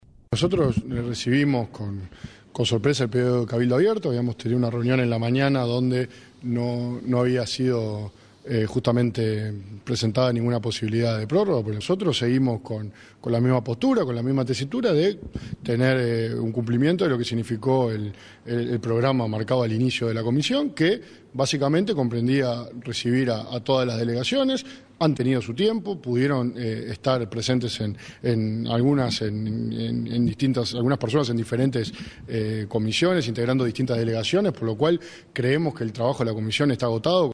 Desde el Partido Nacional entienden que no era necesaria la prórroga, según dijo Pedro Jidonian,  presidente de la Comisión que analiza el proyecto de la reforma de la seguridad social.